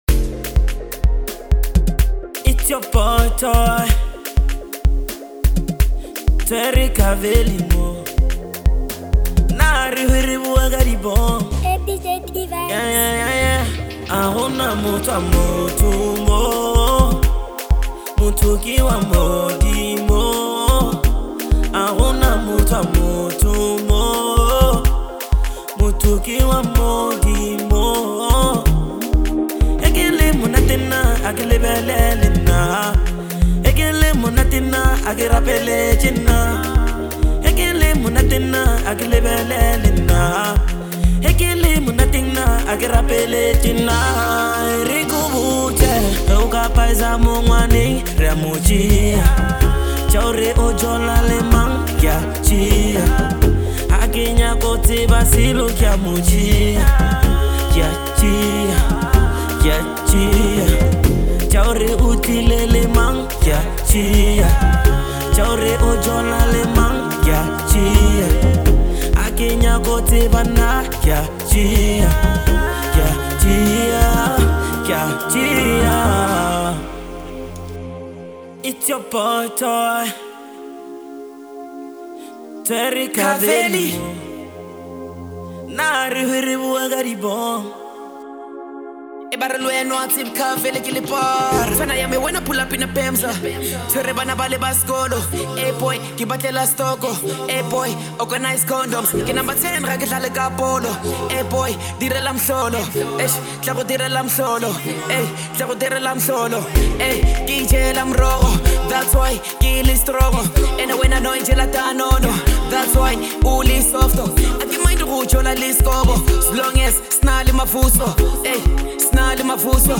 Lekompo banger
on vocals